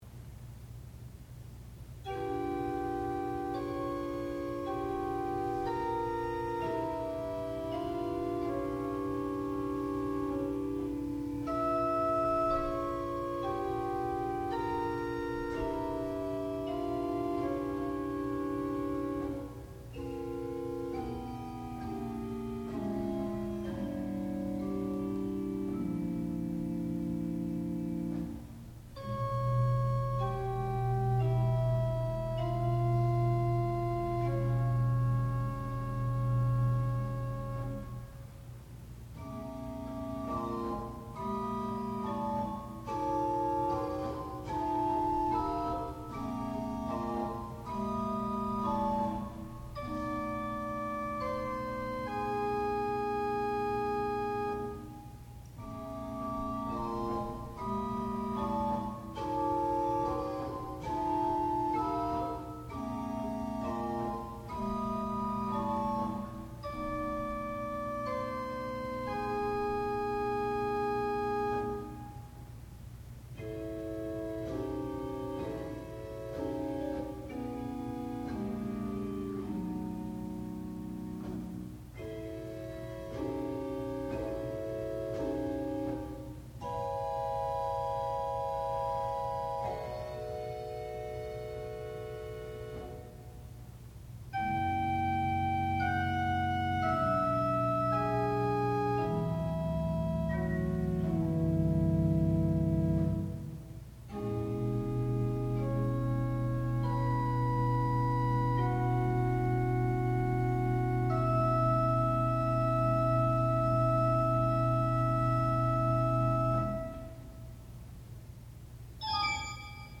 sound recording-musical
classical music
Graduate Recital
organ